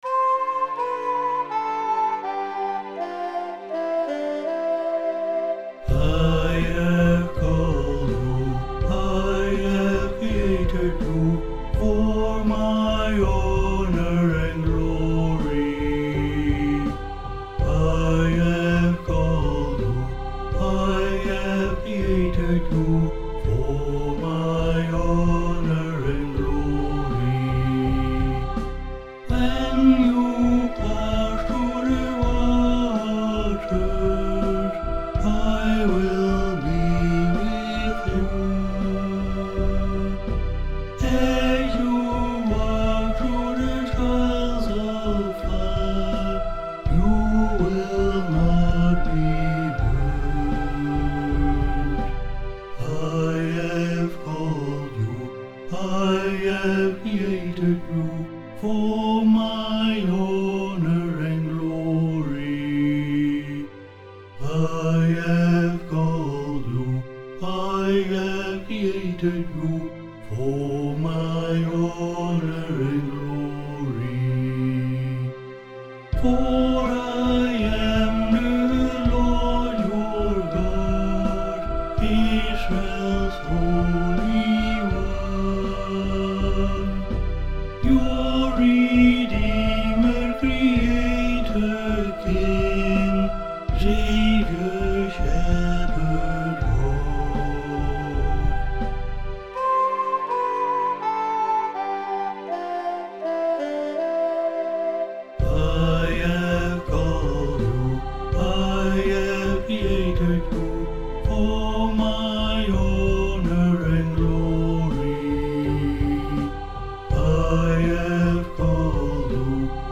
[Karaoke Video with vocal]